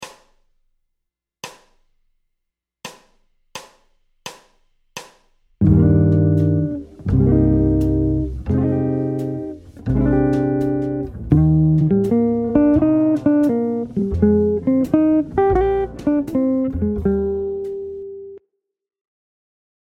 Blue Moon : #1 voicing et solo